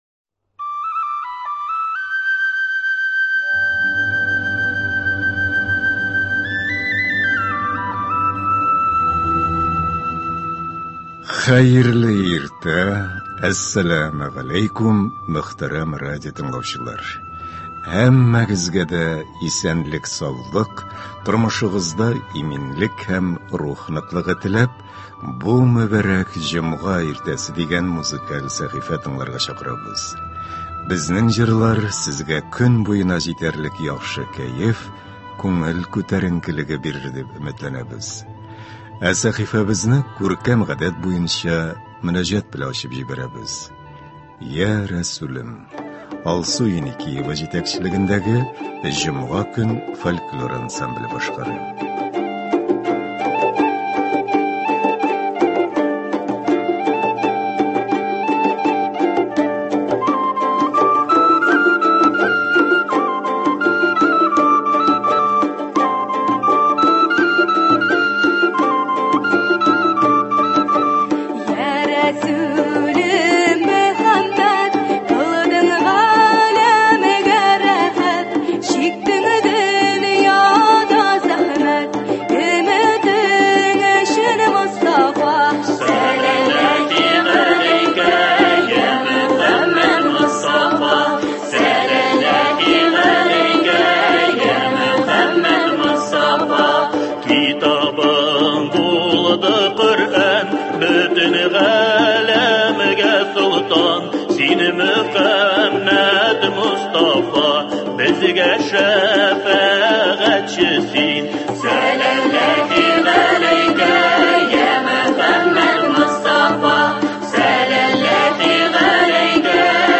Концерт.